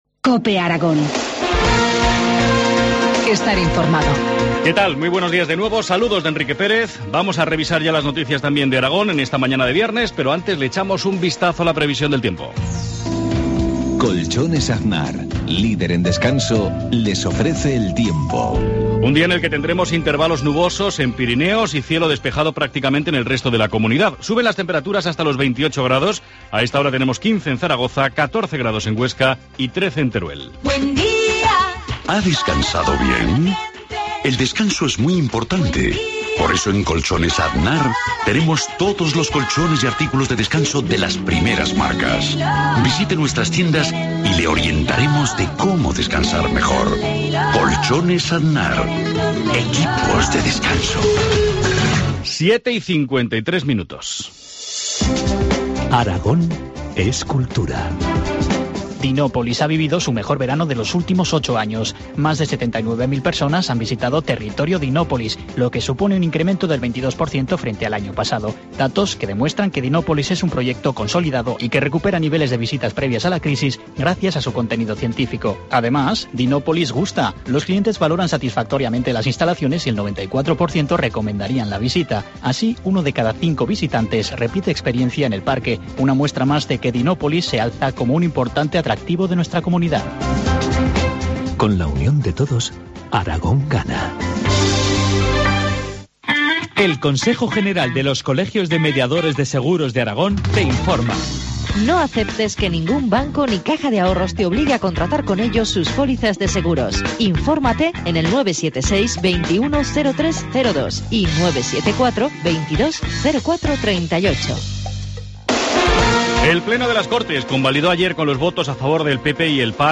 Informativo matinal, viernes 13 de septiembre, 7.53 horas